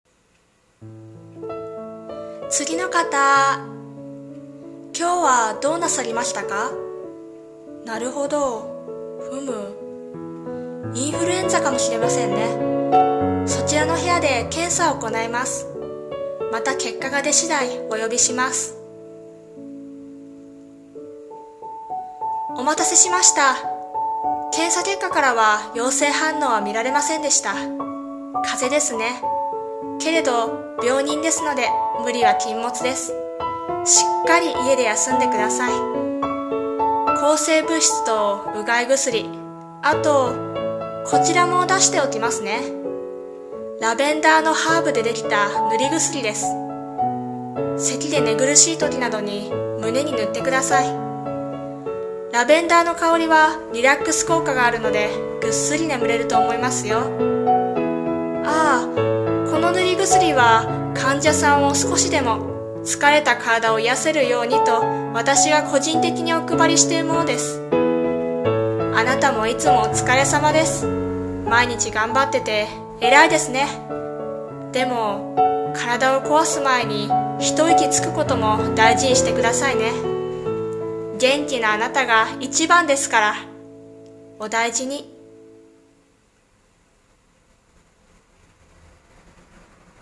さんの投稿した曲一覧 を表示 【声劇】献身的な愛